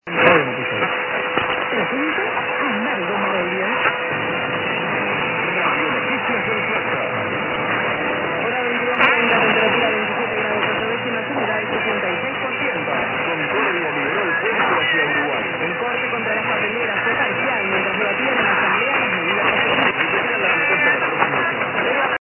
Per la serie "poche ma buone" (e soprattutto rare), riporto alcuni incontri significativi di questi ultimi due giorni e qualche clip (solito consiglio di usare le cuffie per ascoltarli meglio).